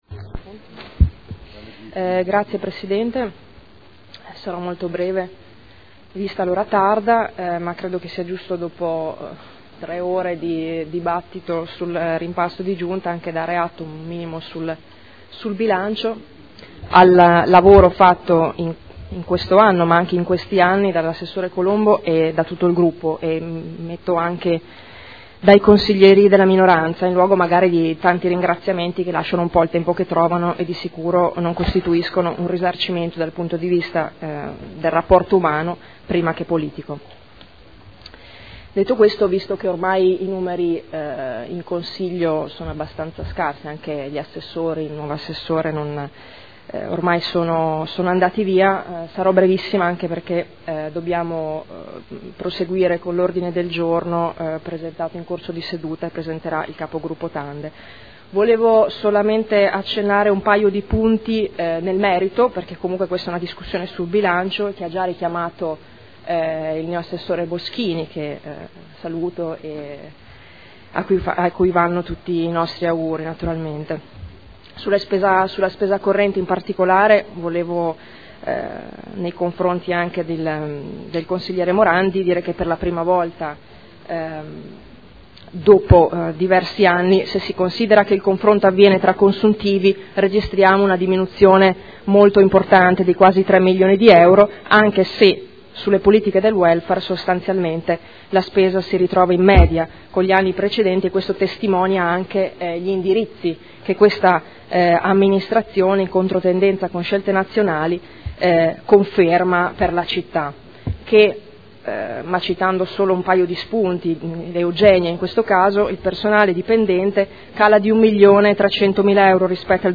Seduta del 23/04/2012. Dibattito su proposta di deliberazione: rendiconto della gestione del Comune di Modena per l’esercizio 2011 – Approvazione